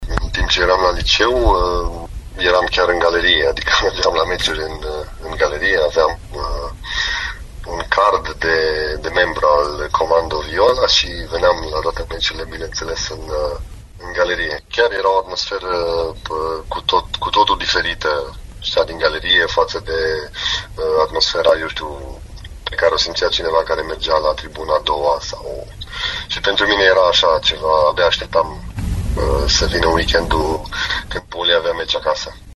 Interviul complet va fi mâine, la ediția de sâmbătă a Arenei Radio.